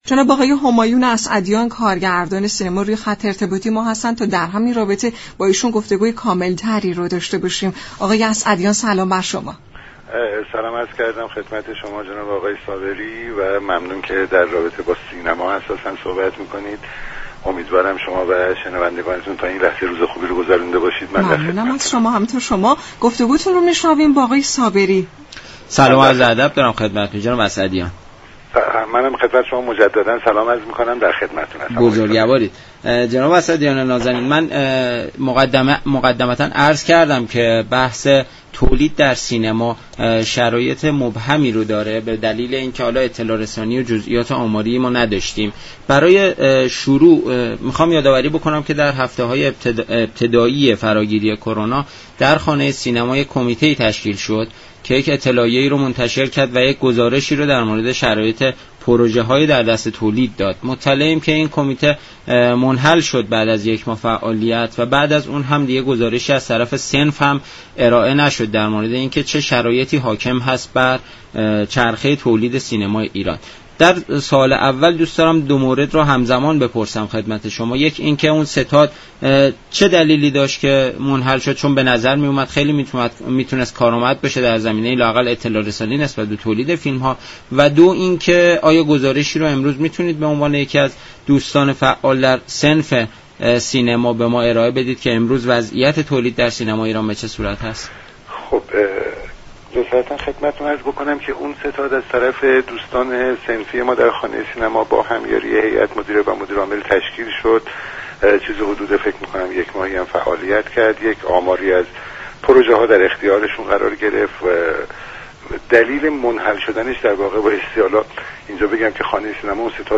همایون اسعدیان كارگردان سینما در حوض نقره گفت: تعطیلی سینما بسیار خطرناك تر از شرایط فعلی است.